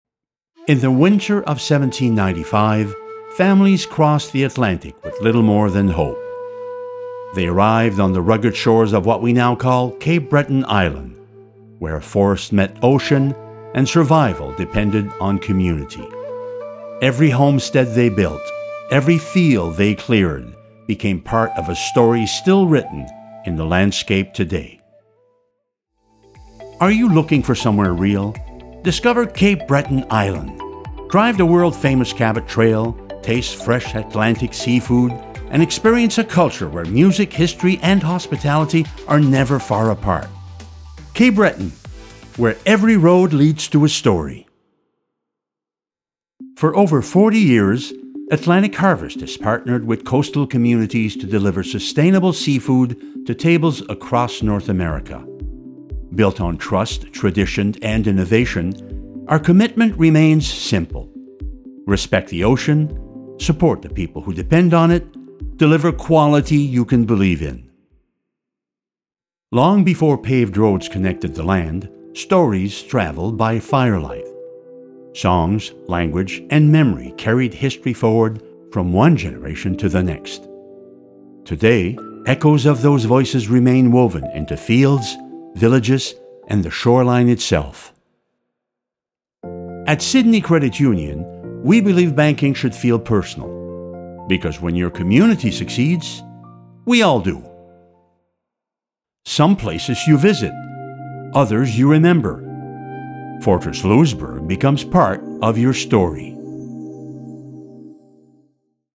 A friendly, conversational tone with a natural storytelling feel.
Demos
Warm & Conversational Storytelling
Neutral North American / Canadian (General)
Middle Aged